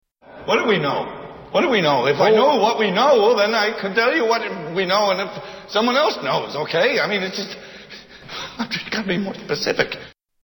Category: Movies   Right: Personal
Tags: Mel Gibson Conspiracy Theory Conspiracy Theory Movie clips Conspiracy Theory Movie Julia Roberts